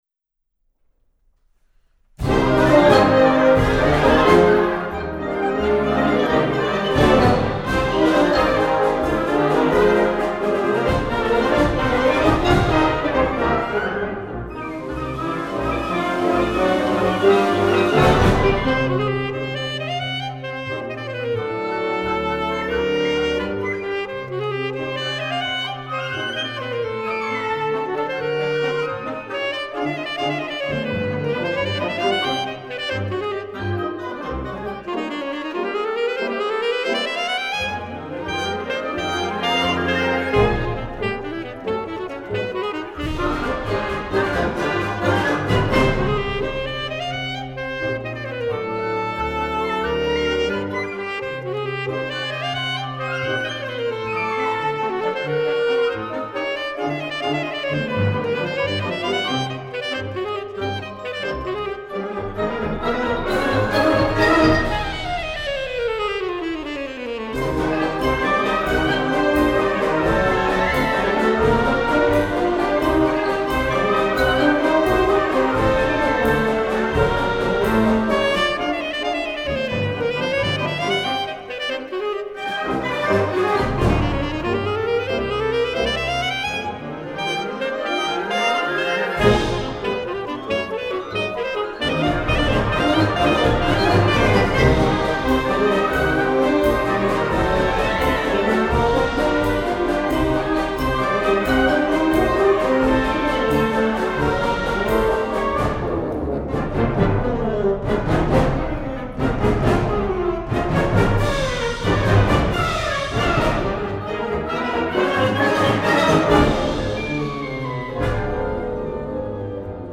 for Alto Saxophone and Wind Orchestra